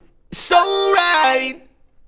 A cappella